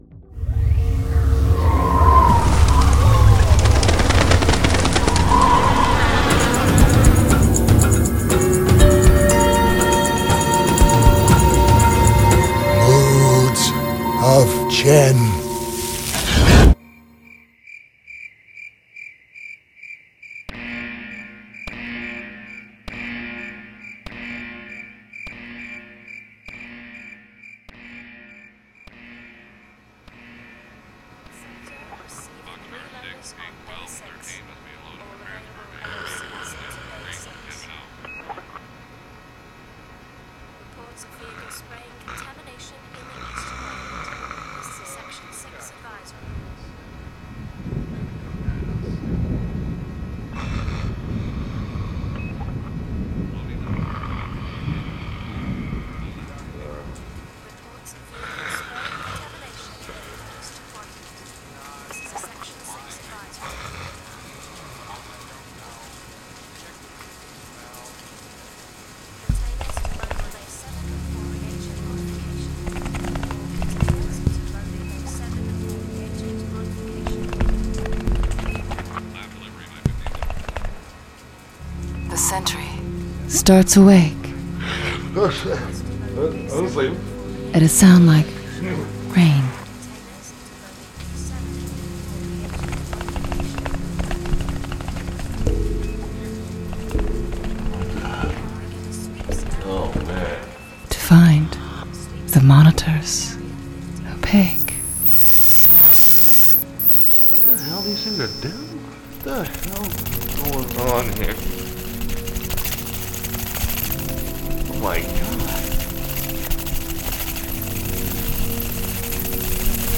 Science and mysticism collide in this cautionary tone poem